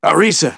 synthetic-wakewords
ovos-tts-plugin-deepponies_Engineer_en.wav